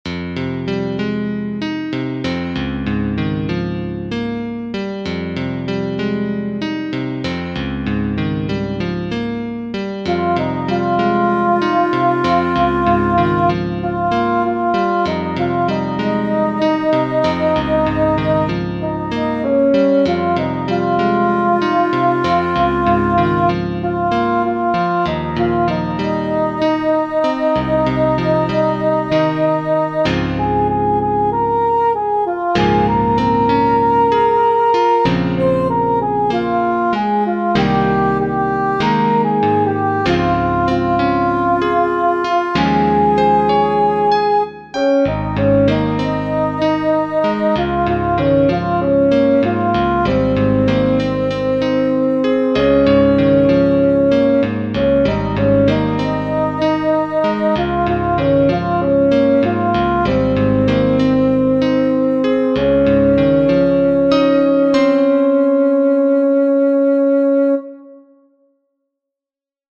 Alto II